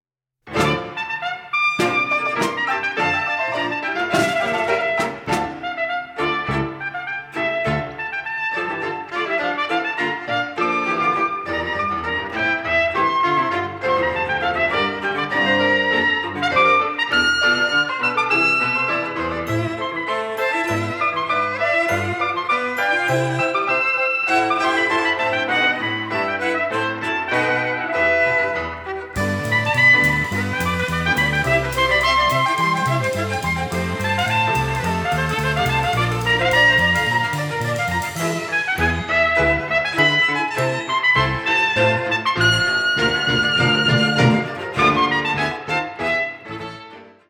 trumpeter
cellist
in a luminous and captivating style